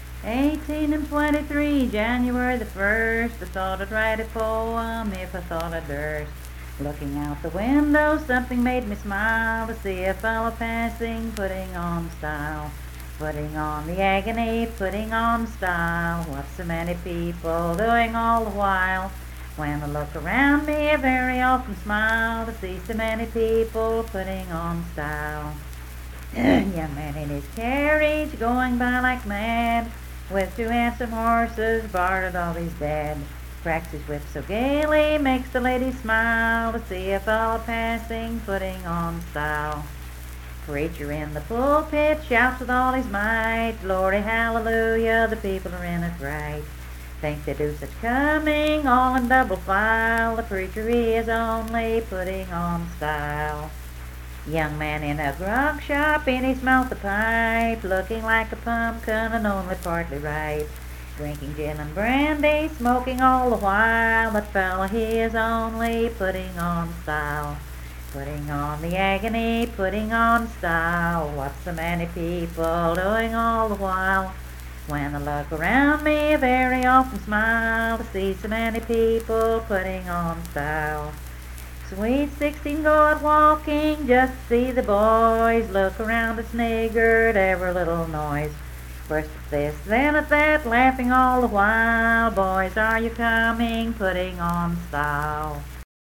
Unaccompanied vocal music
Performed in Coalfax, Marion County, WV.
Voice (sung)